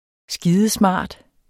Udtale [ ˈsgiːðəˈsmɑˀd ]